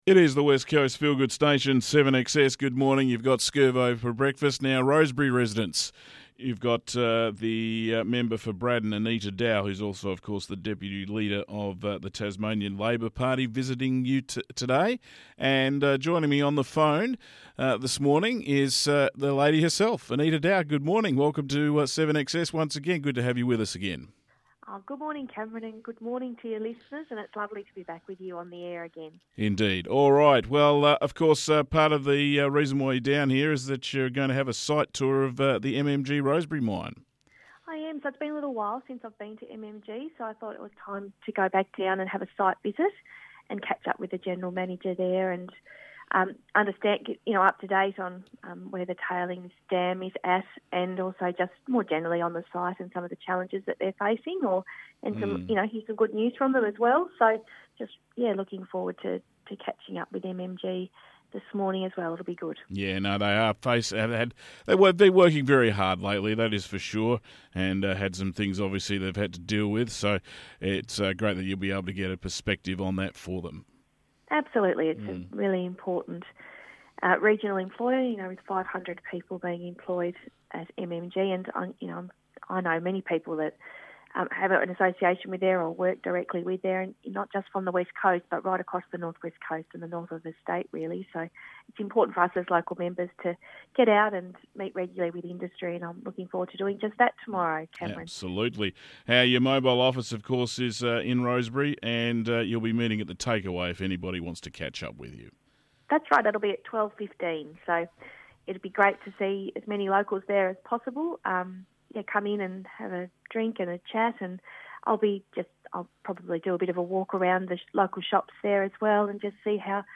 Interview with Member for Braddon and Deputy Leader of the Tasmanian Labour Party Anita Dow